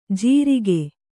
♪ jirike